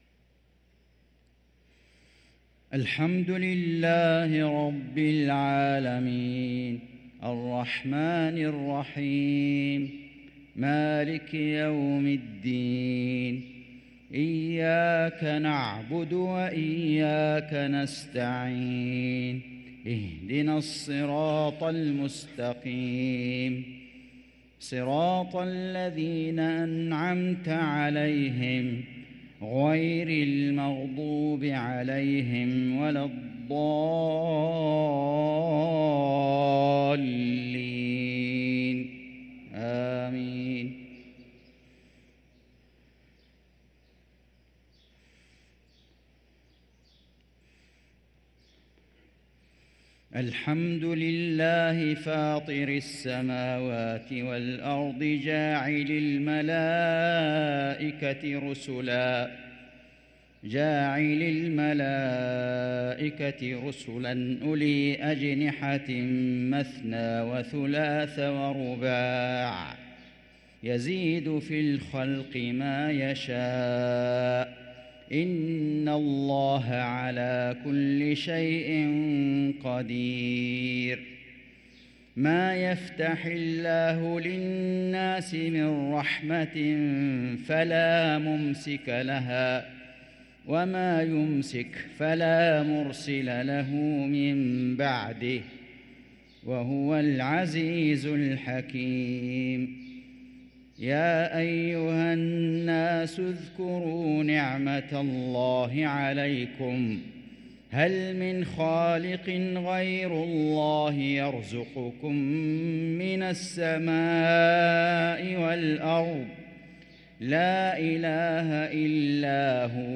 صلاة المغرب للقارئ فيصل غزاوي 4 رجب 1444 هـ
تِلَاوَات الْحَرَمَيْن .